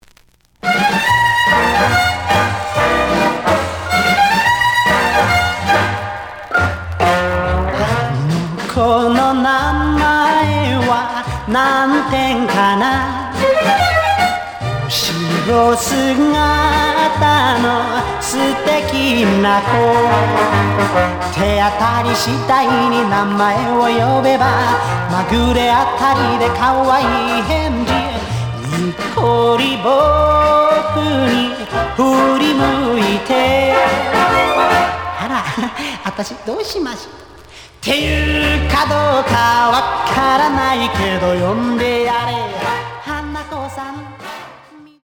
試聴は実際のレコードから録音しています。
The audio sample is recorded from the actual item.
●Format: 7 inch
●Genre: Rock / Pop